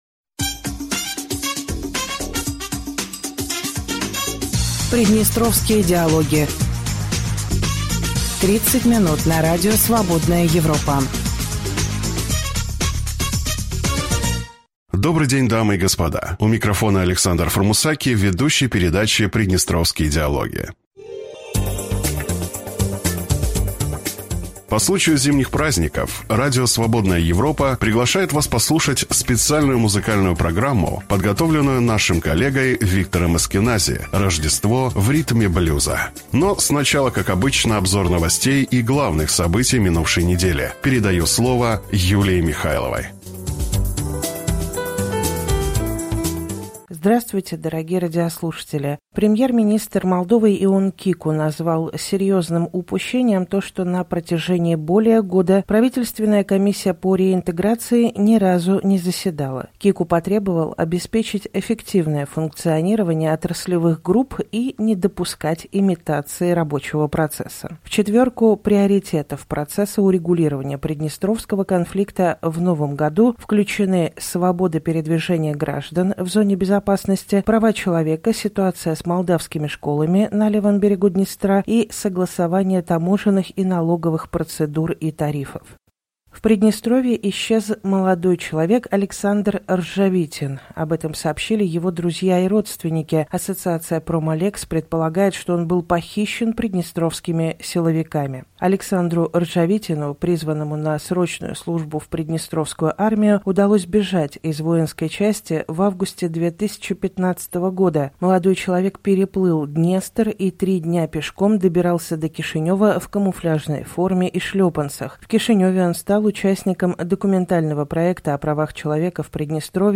По традиции, в период зимних праздников Радио Свободная Европа приглашает вас послушать специальную музыкальную программу. Но сначала, как обычно, обзор новостей и главных событий...